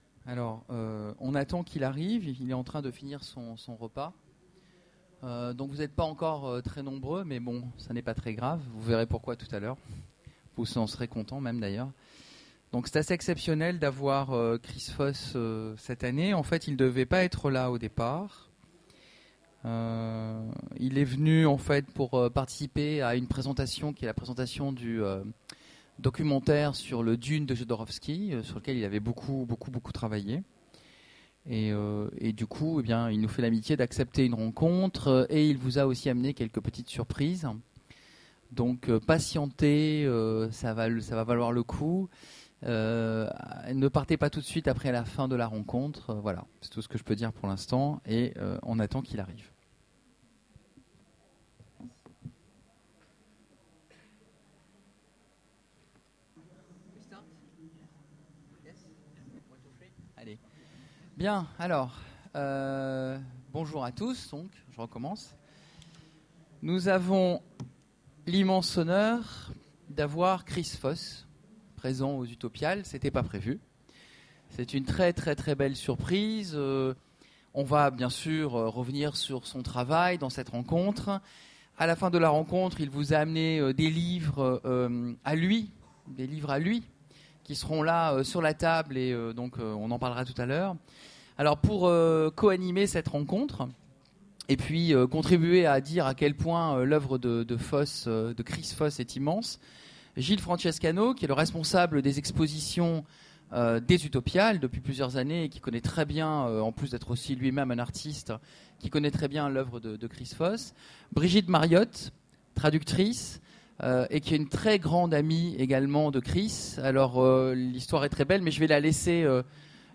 Utopiales 13 : Conférence Rencontre avec Chris Foss
- le 31/10/2017 Partager Commenter Utopiales 13 : Conférence Rencontre avec Chris Foss Télécharger le MP3 à lire aussi Chris Foss Genres / Mots-clés Rencontre avec un auteur Conférence Partager cet article